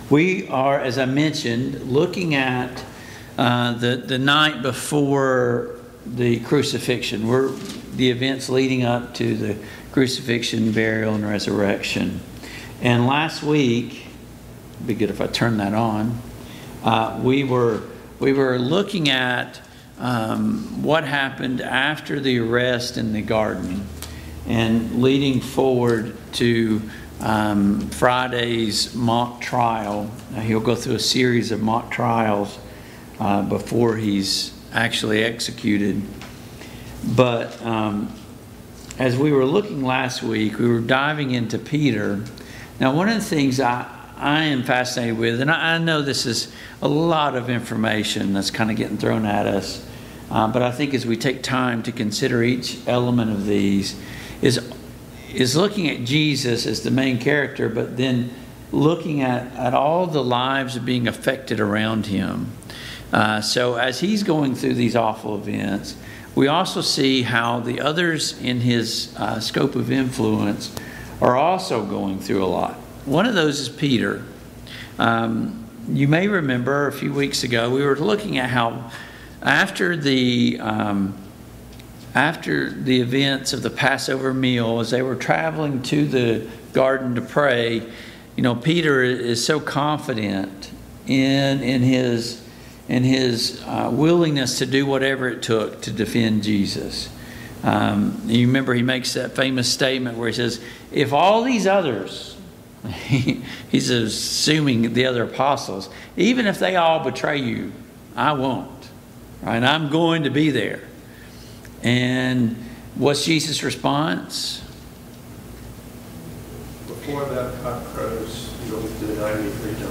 Mid-Week Bible Study Download Files Notes Topics: Peter's Denial of Jesus « 2.